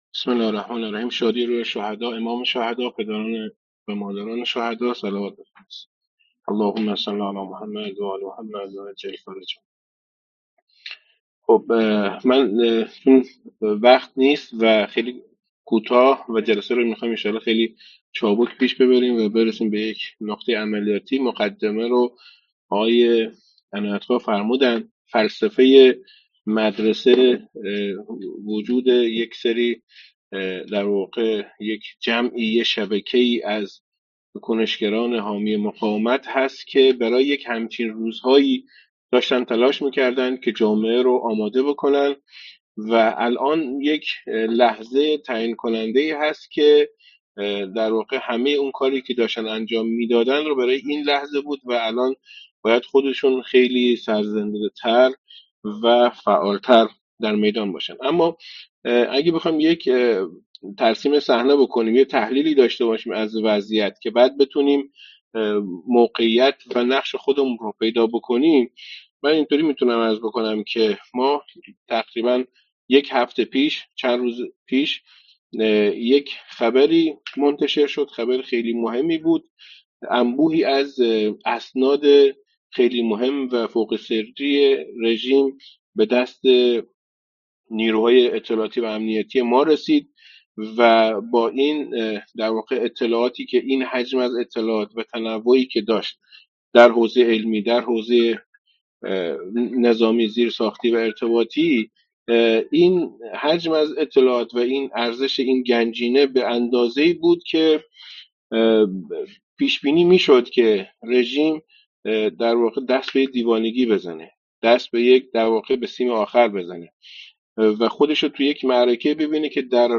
صوت جلسه با موضوع : در این شرایط چه کنیم؟